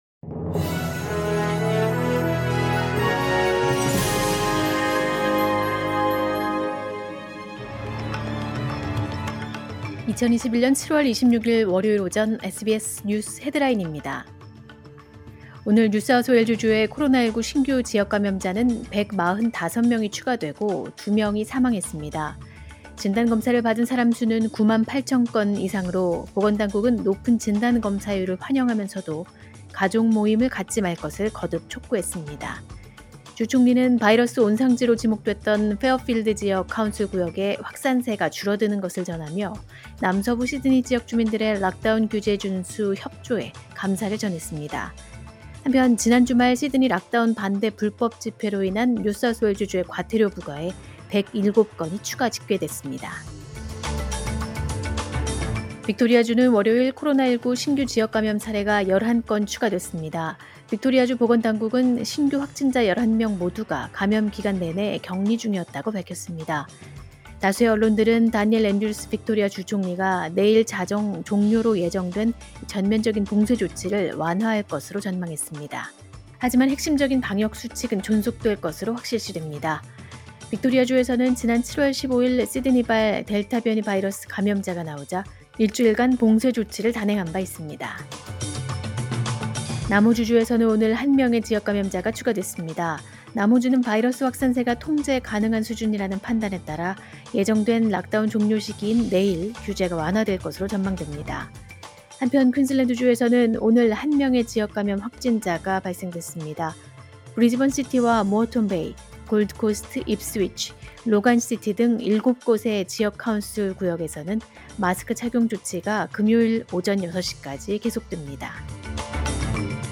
2021년 7월 26일 월요일 오전의 SBS 뉴스 헤드라인입니다.